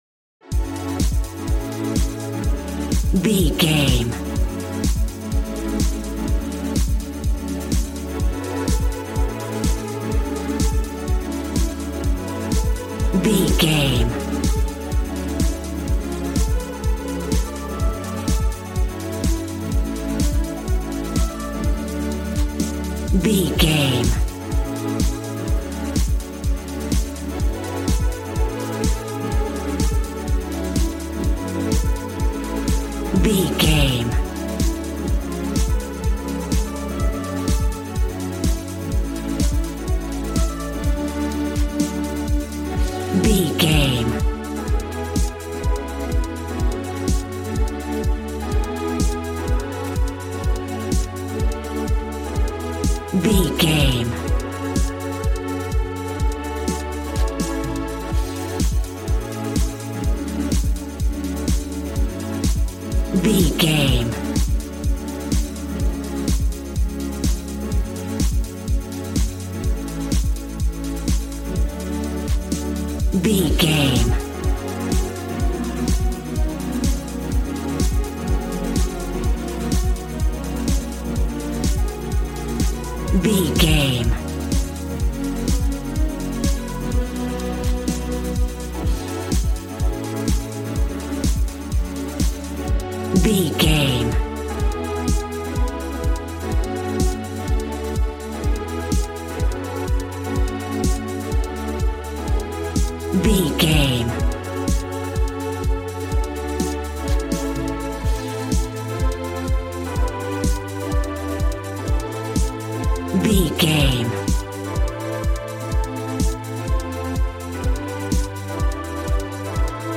Aeolian/Minor
G♭
Fast
uplifting
lively
groovy
synthesiser
drums